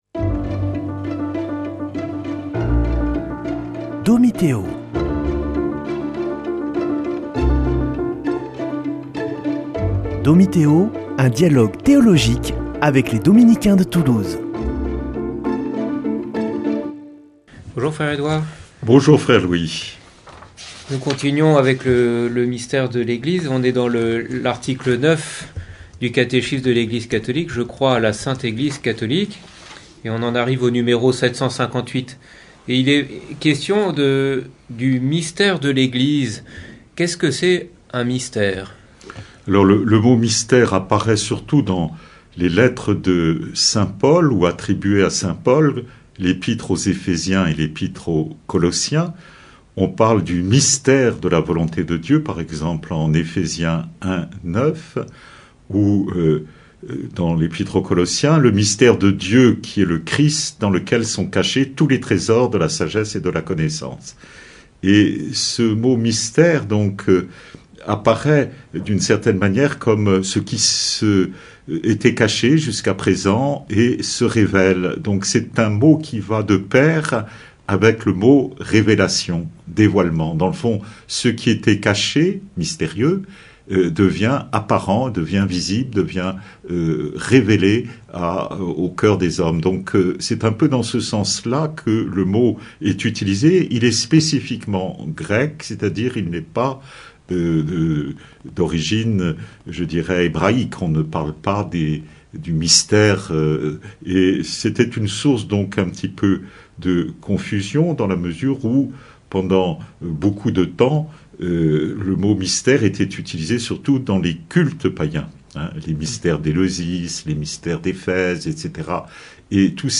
Une émission présentée par Dominicains de Toulouse Frères de la communauté